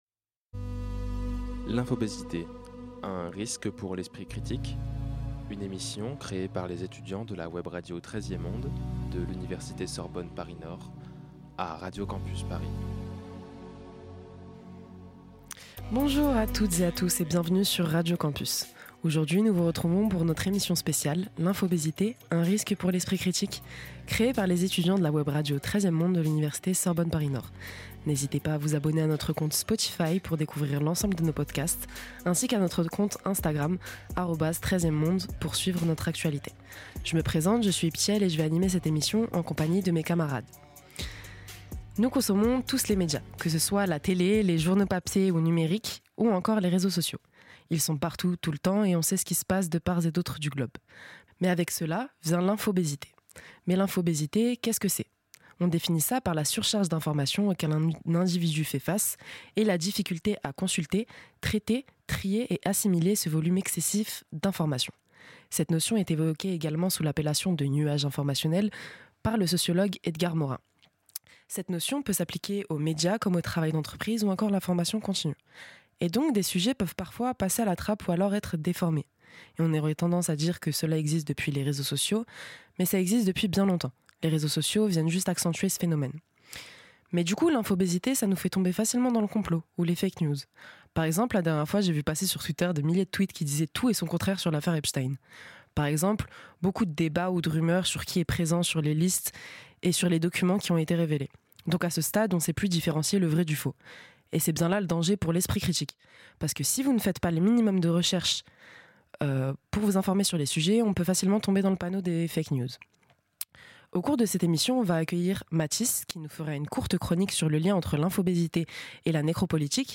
Cette semaine, Radio Campus Paris reçoit la Web Radio 13e Monde pour une émission spéciale sur l'infobésité et les risques que ce phénomène représente pour toutes et tous.